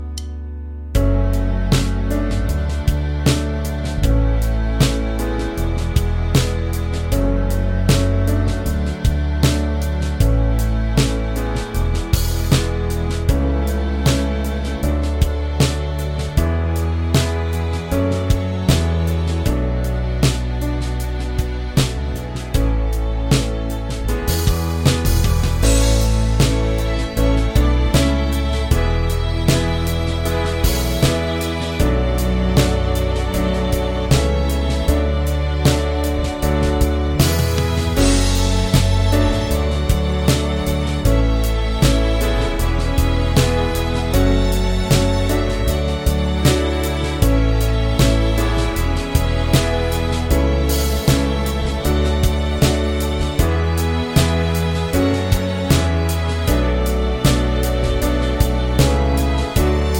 Minus Main Guitar For Guitarists 3:08 Buy £1.50